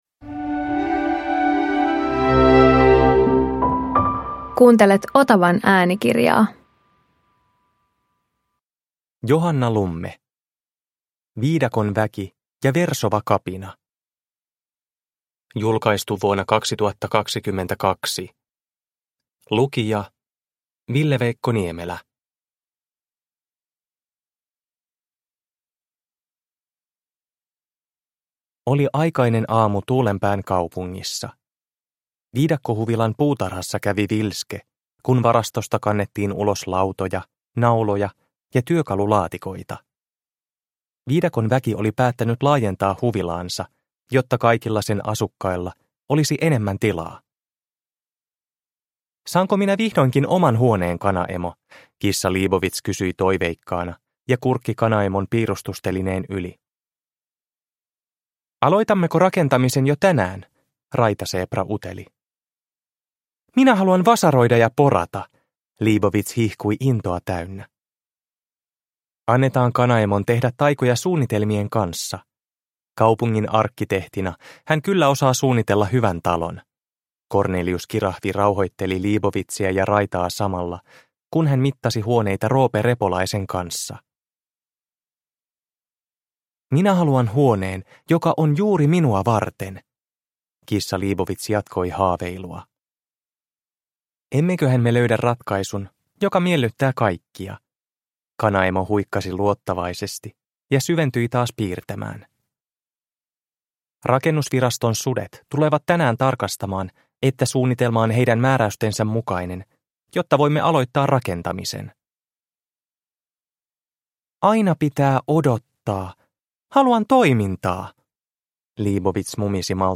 Viidakon väki ja versova kapina – Ljudbok